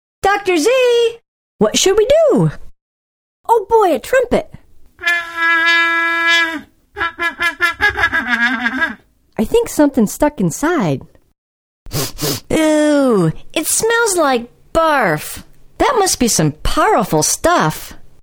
Project: Voices and music for online reading program